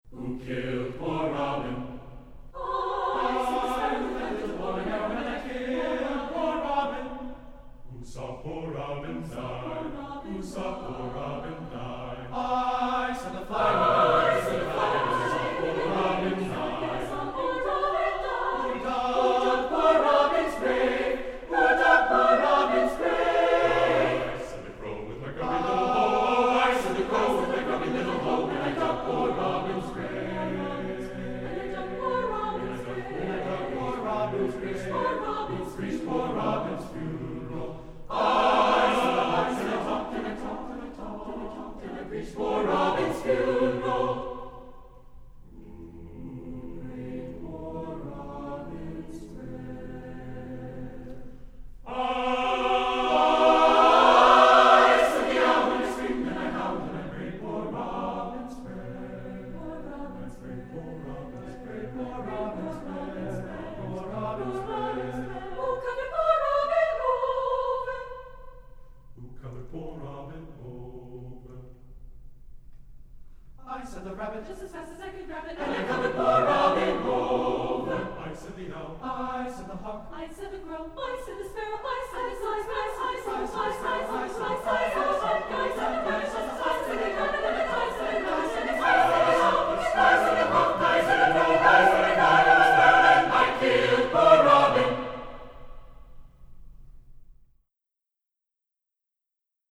Accompaniment:      A Cappella
Music Category:      Choral
tremendously fun and rowdy folksong